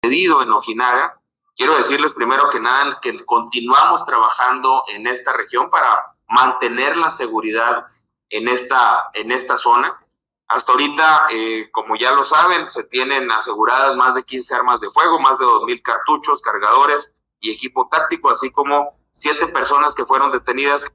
AUDIO: GILBERTO LOYA , SECRETARIO DE SEGURIDAD PÚBLICA DEL ESTADO (SSPE)